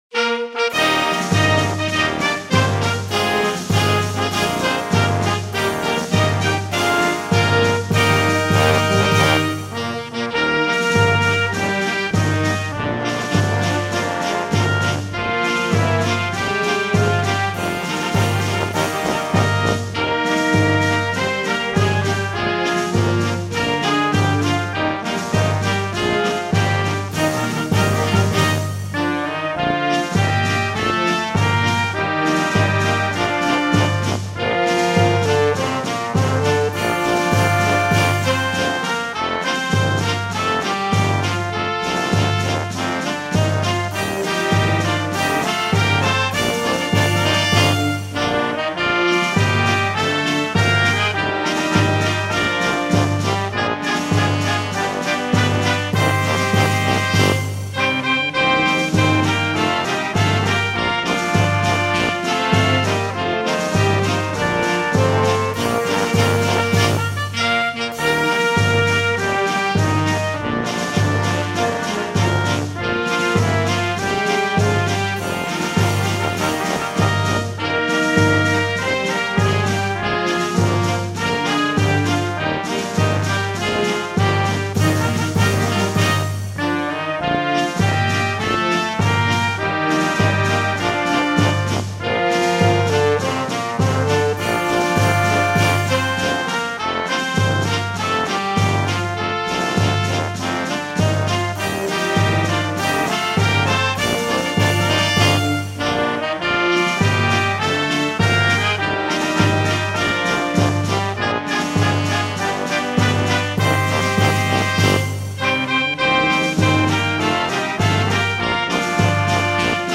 Hino Campina Verde - Instrumental